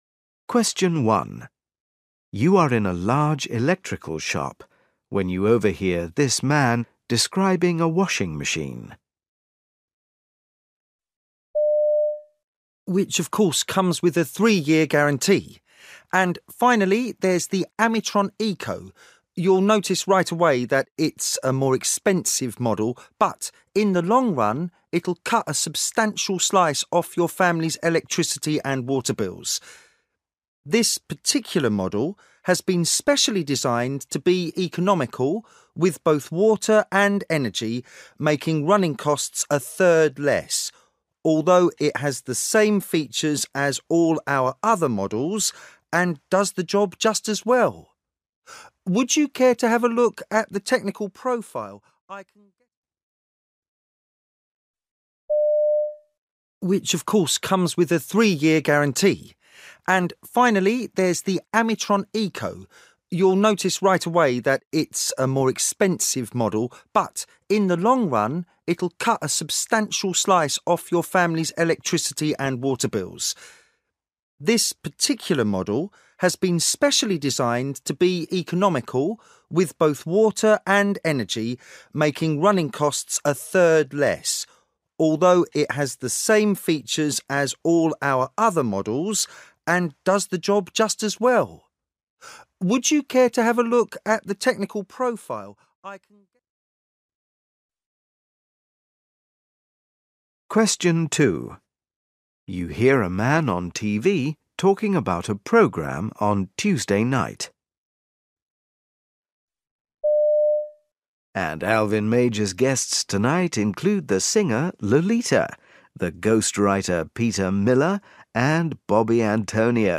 Listening Part 1 You will hear people talking in eight different situations.